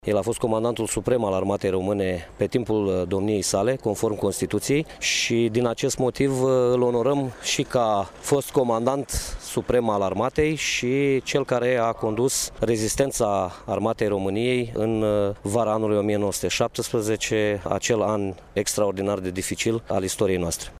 În prezenţa oficialităţilor locale şi judeţene, astăzi, la sediul Brigăzii 15 Mecanizată Podu Înalt de la Iaşi a vut loc dezvelirea plăcii comemorative în amintirea Regelui Ferdinand I.